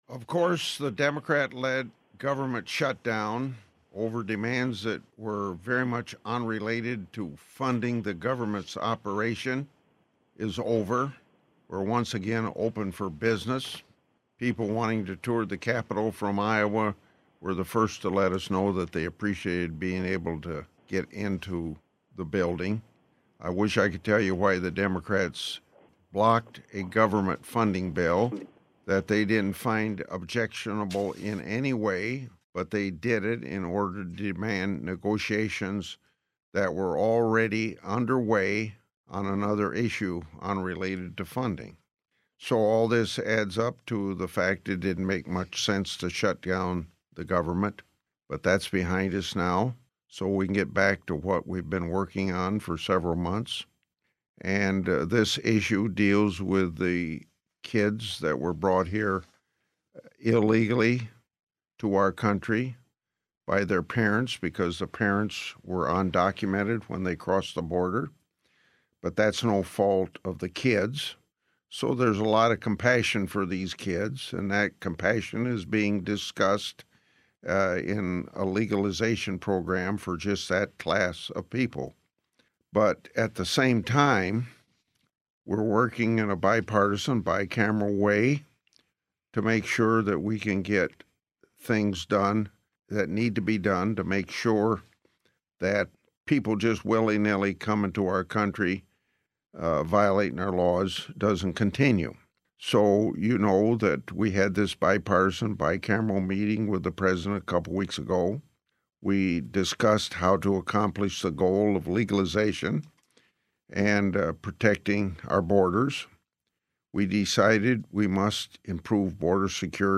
Radio Networks Call